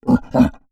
MONSTER_Effort_02_mono.wav